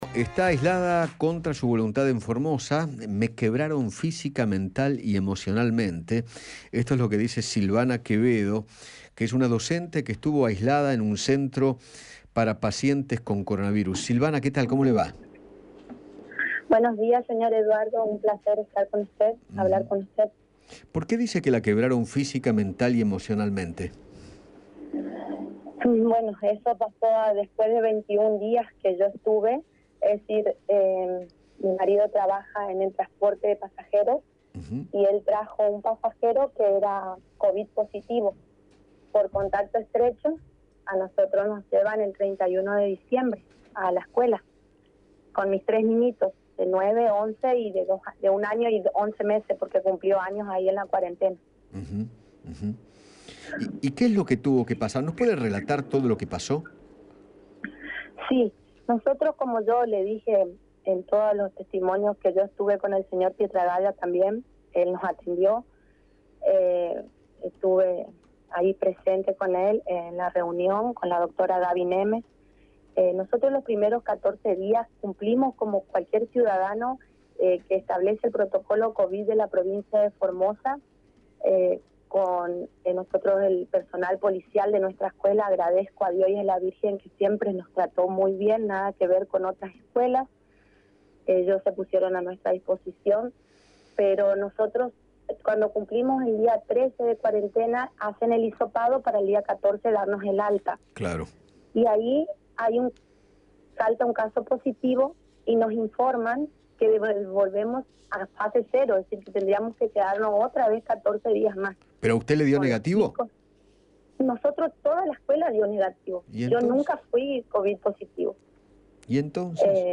El testimonio de una docente que fue aislada obligadamente en Formosa - Eduardo Feinmann